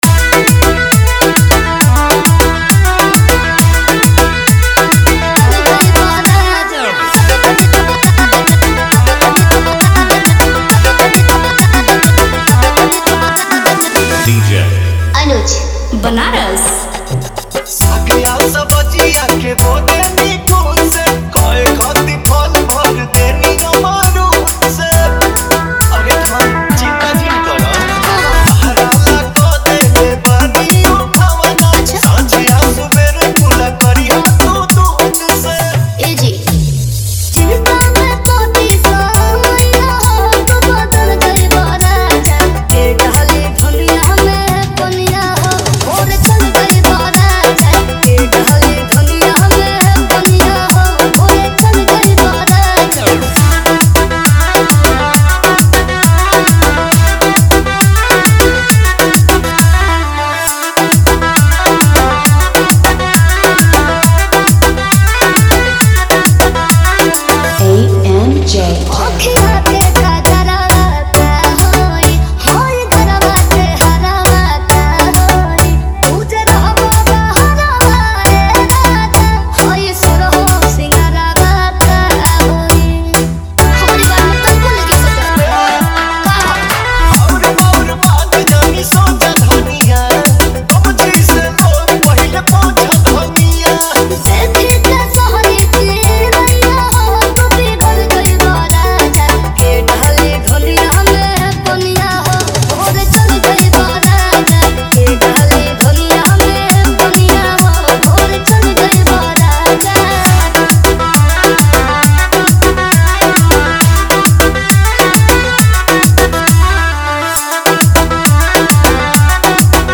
ओरिजिनल वोकल: पॉपुलर भोजपुरी सिंगर
कैटेगरी: भोजपुरी डांस रीमिक्स
ड्यूरेशन: फुल पार्टी वर्जन